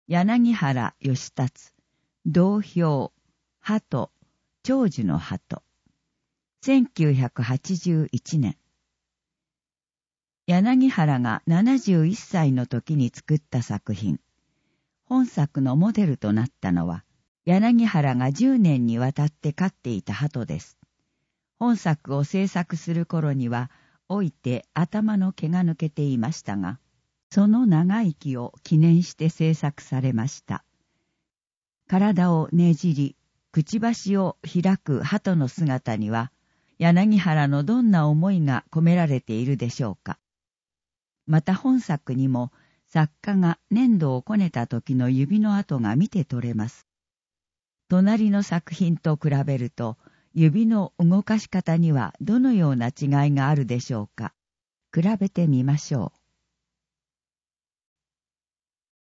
音訳